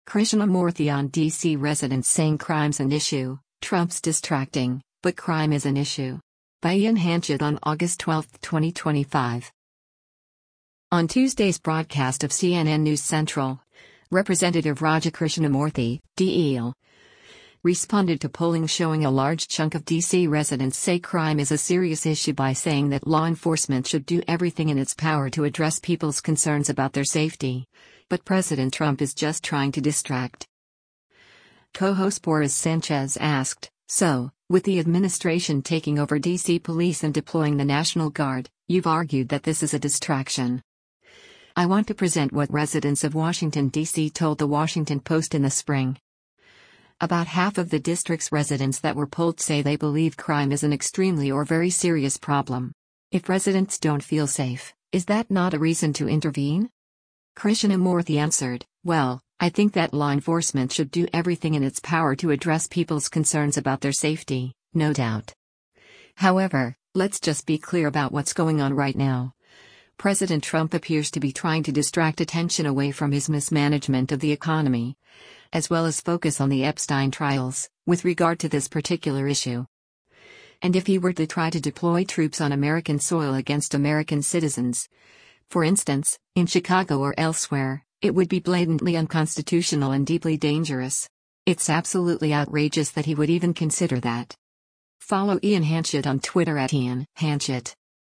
On Tuesday’s broadcast of “CNN News Central,” Rep. Raja Krishnamoorthi (D-IL) responded to polling showing a large chunk of D.C. residents say crime is a serious issue by saying that “law enforcement should do everything in its power to address people’s concerns about their safety,” but President Trump is just trying to distract.